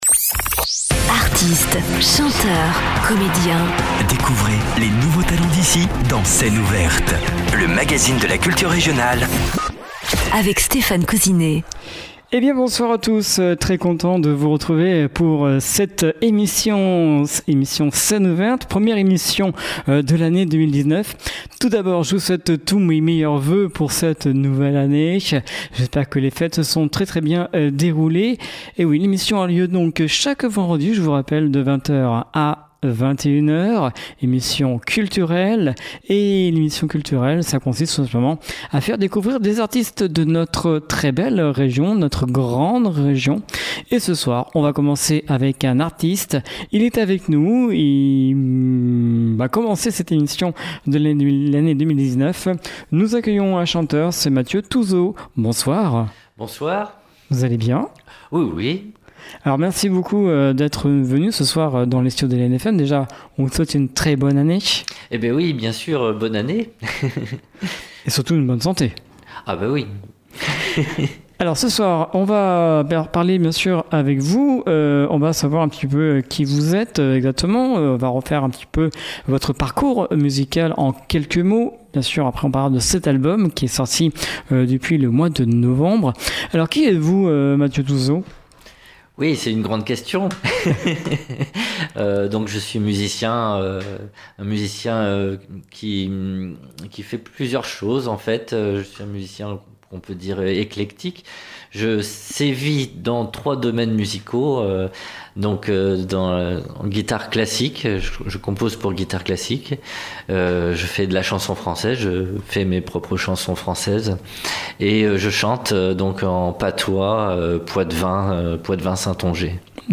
Rêveur et poète, son amour des mots, sa voix touchante au timbre si particulier…